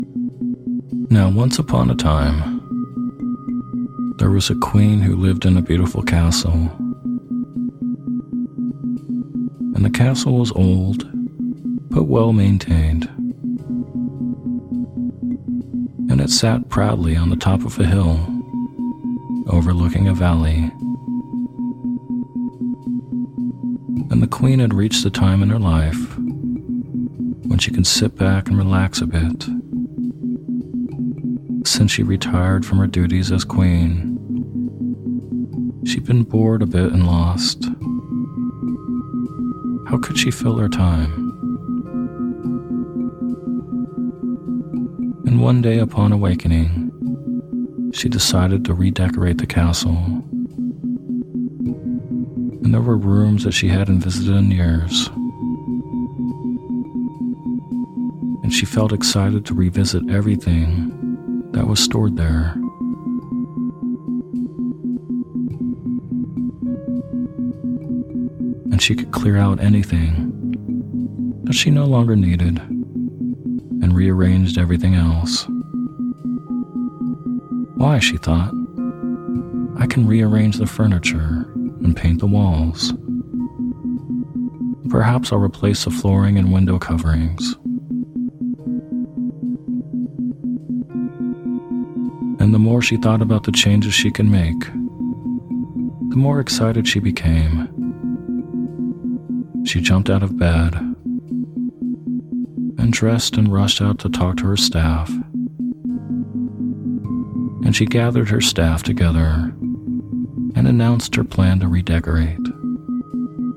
Story Based Meditation "Redecorating The Castle" With Isochronic Tones